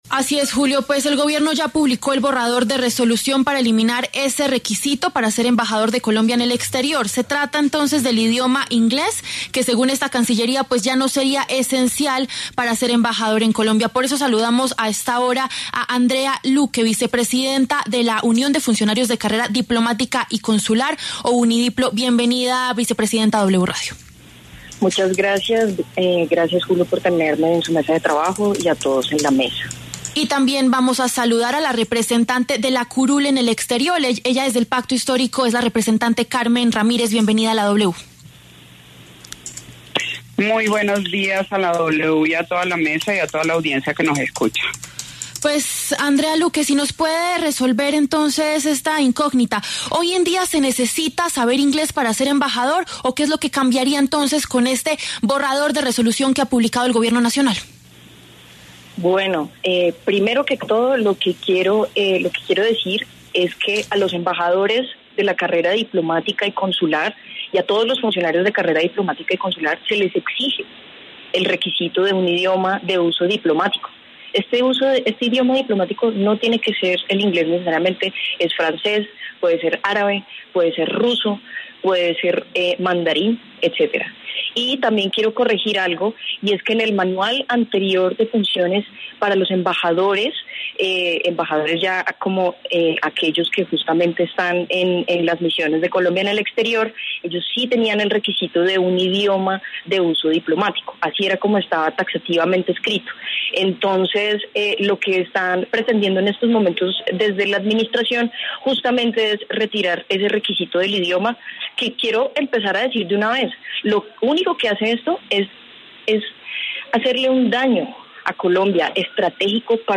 En los micrófonos de La W